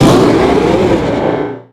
Cri de Méga-Latios dans Pokémon Rubis Oméga et Saphir Alpha.
Cri_0381_Méga_ROSA.ogg